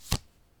landingMedHard.ogg